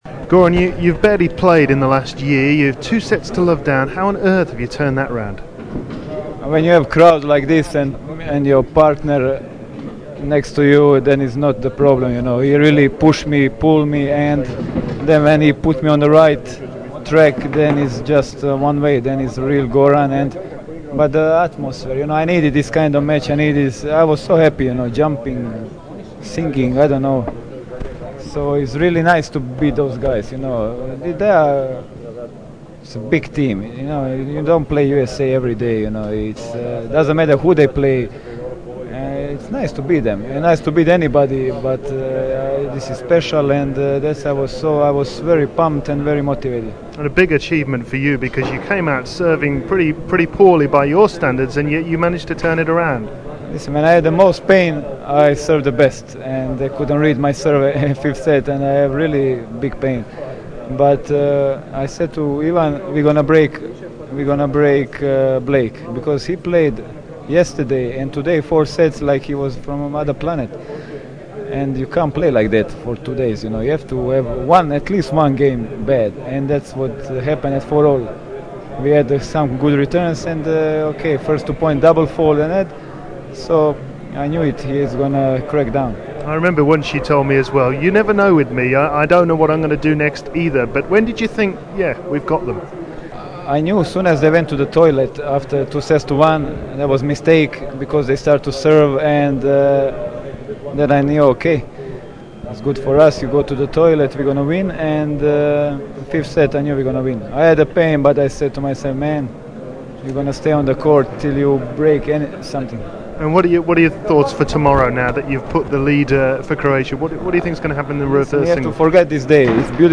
Davis Cup - 8 February 2003 - Interview with Goran Ivanisevic [Audio Interview] NEW!!